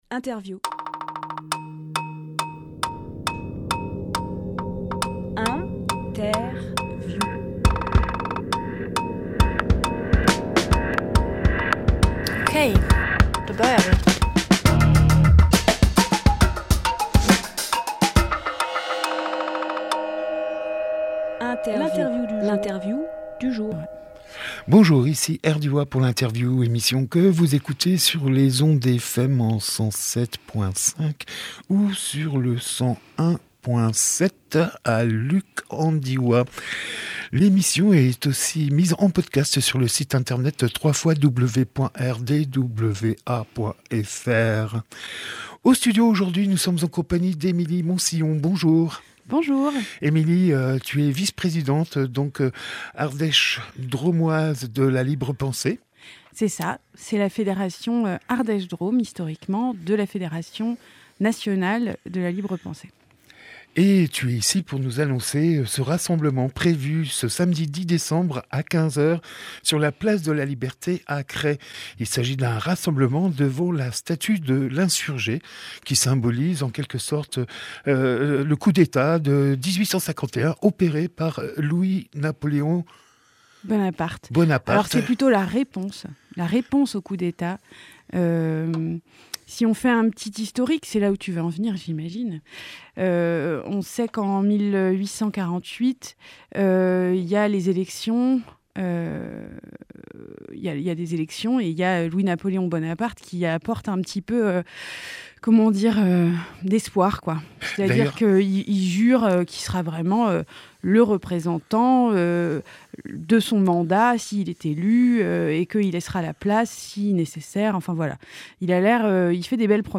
Emission - Interview Hommage à l’Insurgé au Coup d’État de 1851 Publié le 8 décembre 2022 Partager sur…
07.12.22 Lieu : Studio RDWA Durée